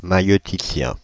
Ääntäminen
Synonyymit homme sage-femme sage-homme sage-femme accoucheur obstétricien sage-femme homme Ääntäminen France (Île-de-France): IPA: /ma.jø.ti.sjɛ̃/ Haettu sana löytyi näillä lähdekielillä: ranska Käännöksiä ei löytynyt valitulle kohdekielelle.